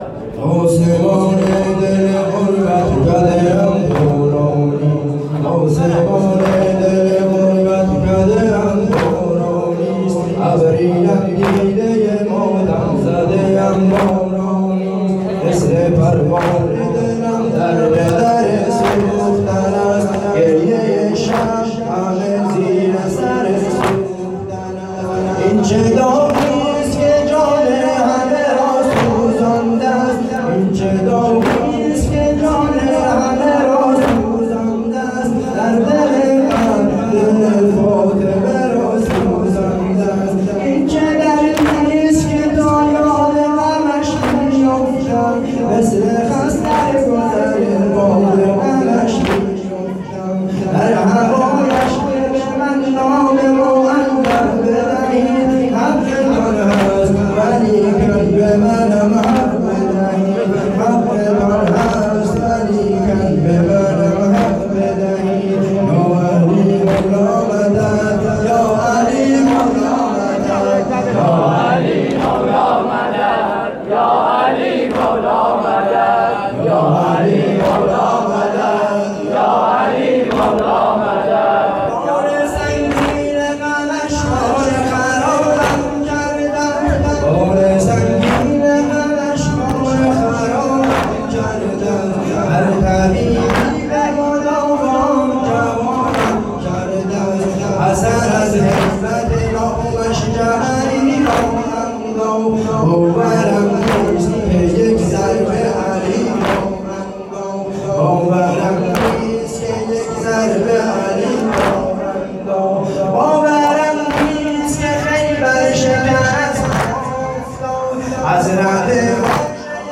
شب اول قدر (19رمضان) 1440